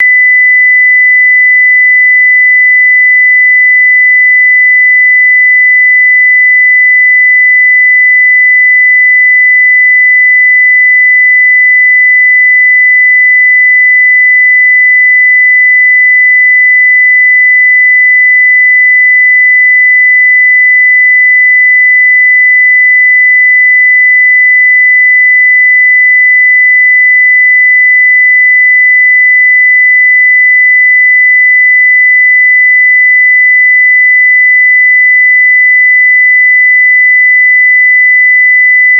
周波数2000Hz 着信音の試聴とダウンロード
60歳以上も聞こえる音。ソプラノ歌手の歌声と同じほどの周波数と言われています。